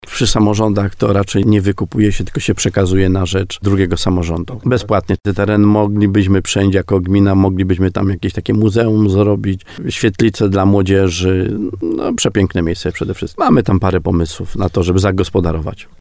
Jak mówi wójt Stanisław Kuzak trwają już rozmowy w tej sprawie.